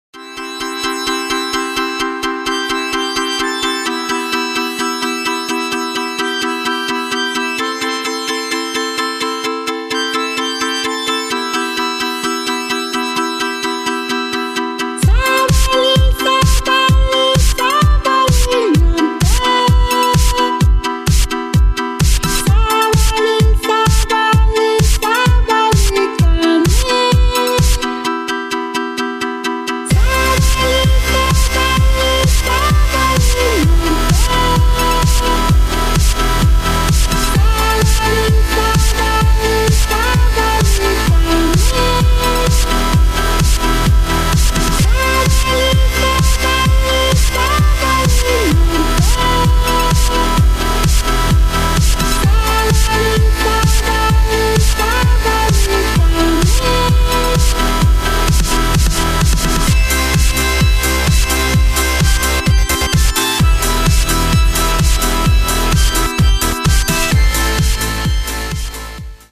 • Качество: 128, Stereo
громкие
женский голос
dance
Electronic
электронная музыка
house
electro house